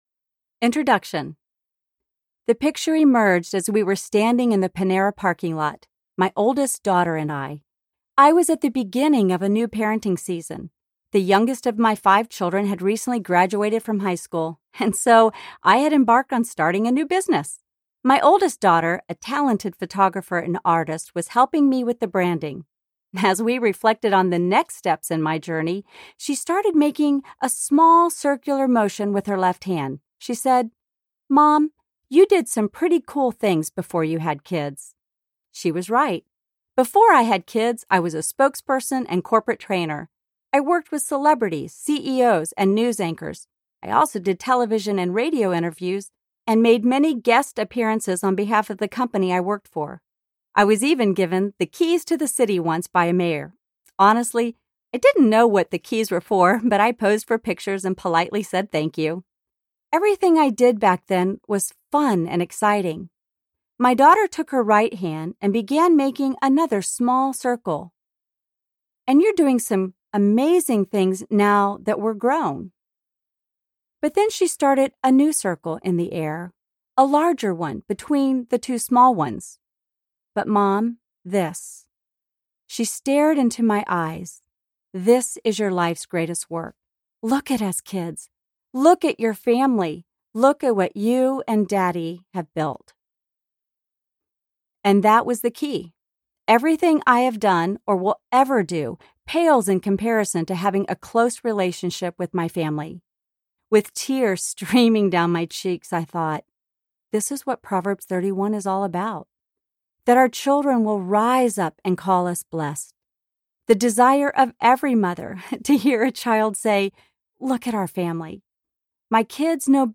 Parenting Beyond the Rules Audiobook